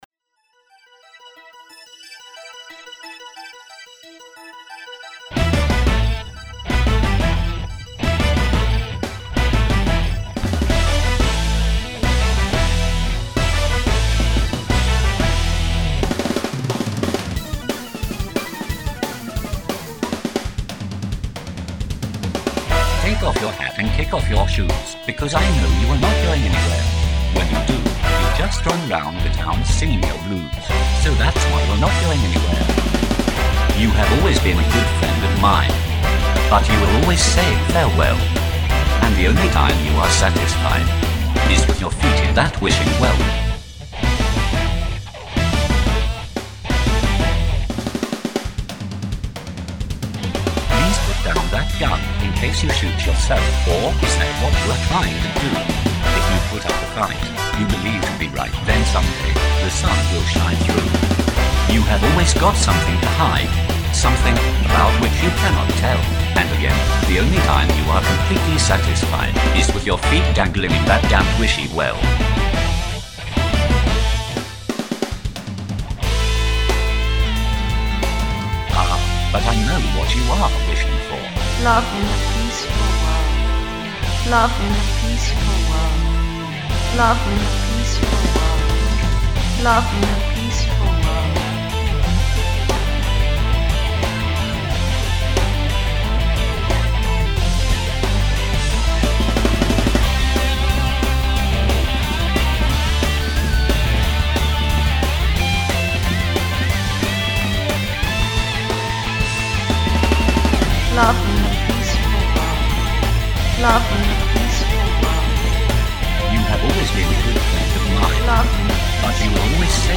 cover version
rock